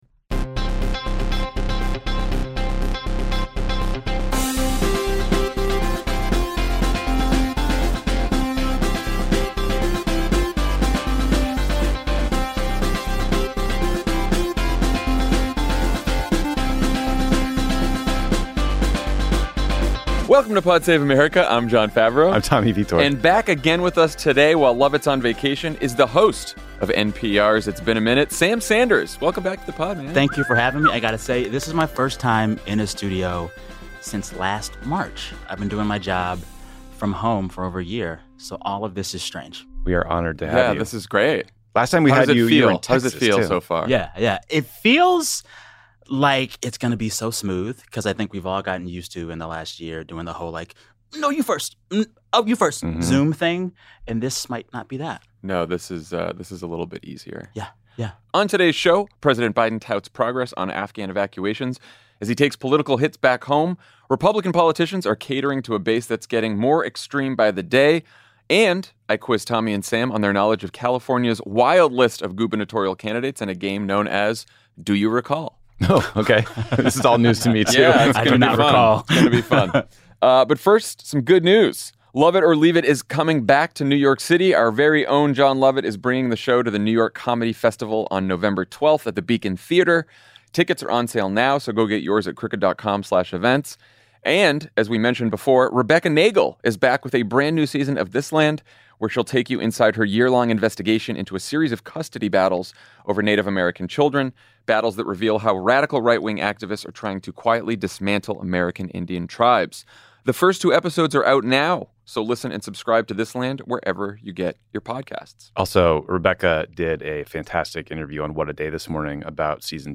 NPR’s Sam Sanders joins Jon Favreau and Tommy Vietor to talk about the political fallout and lessons (not) learned from Afghanistan, Democratic anxiety over the midterms, and the increasing radicalism of the Republican base. Then, Jon quizzes Tommy and Sam on their knowledge of California’s wild list of gubernatorial candidates in a game called “Do You Recall?”